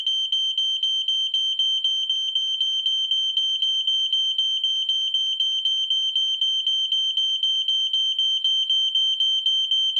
ei650i-rauchmelder-alarm.mp3